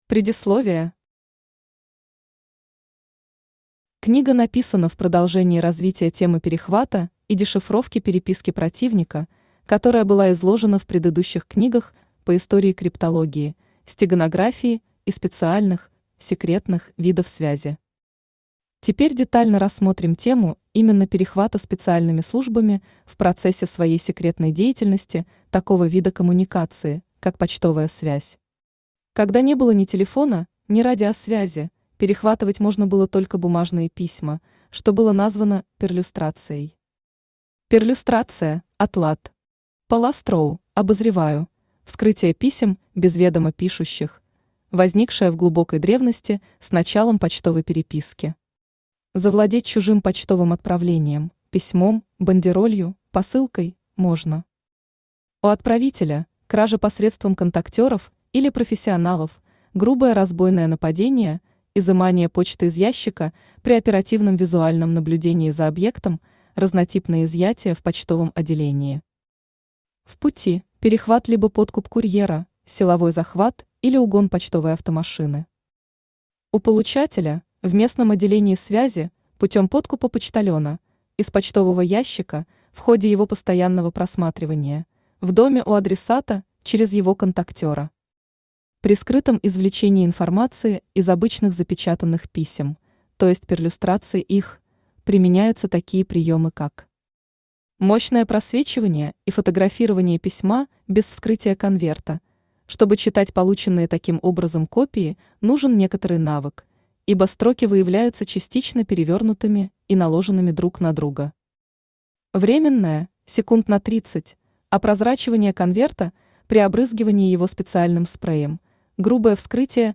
Перехват информации Автор Вадим Гребенников Читает аудиокнигу Авточтец ЛитРес.